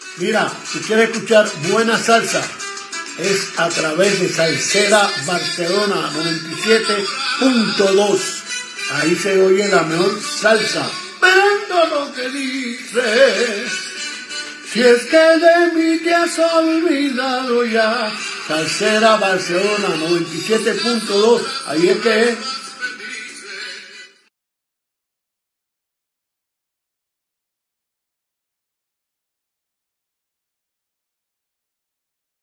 Salutació del cantant Andy Montañez